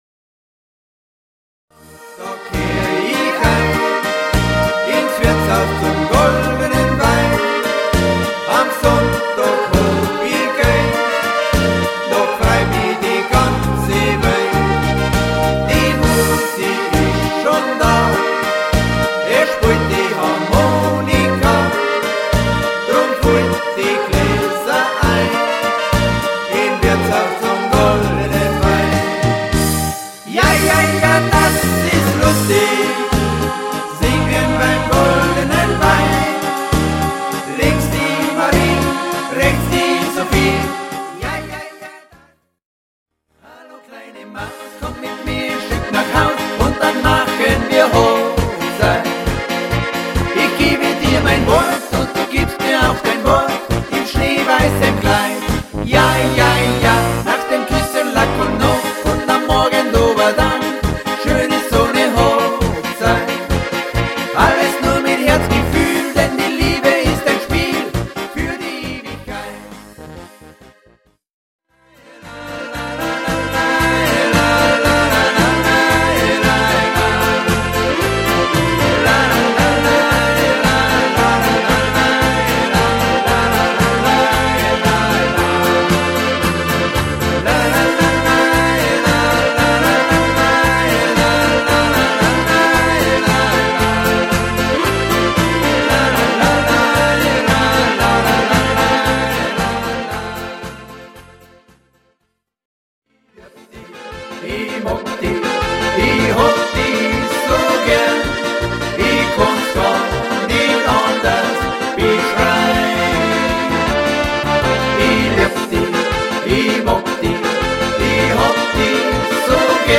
Livemusik für jeden Anlass
• Alleinunterhalter
• 8 Demo’s; Von Bayern bis nach Oberkrain (Gemischt)